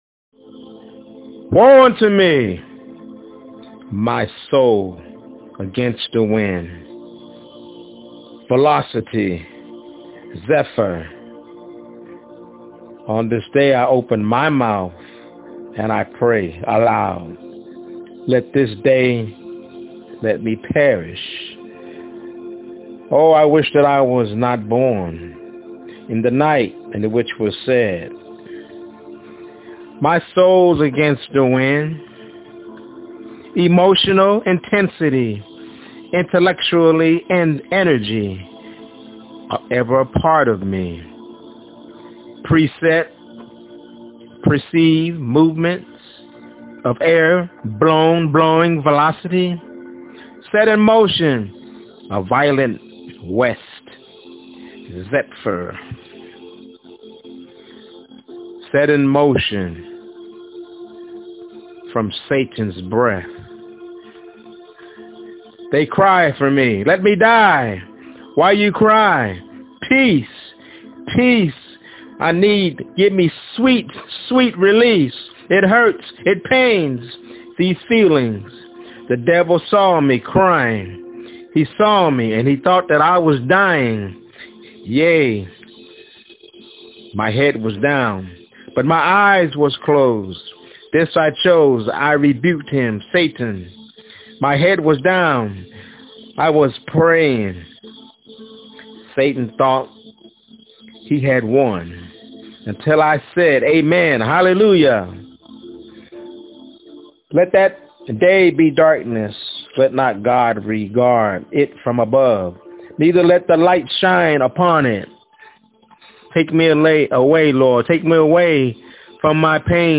My Soul Against the Wind- My Spokenword